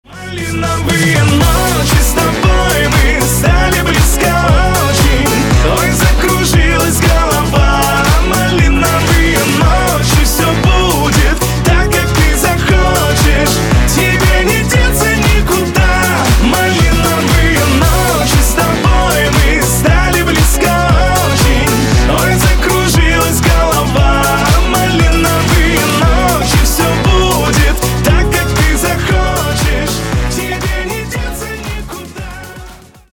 поп
эстрада